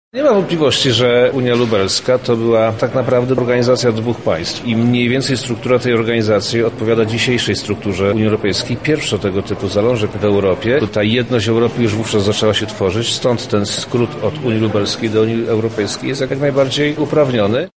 W auli Auditorium Maximum Uniwersytetu Medycznego w Lublinie odbyła się konferencja poświęcona 450. rocznicy zawarcia Unii Lubelskiej.
Mówi Przemysław Czarnek, Wojewoda Lubelski.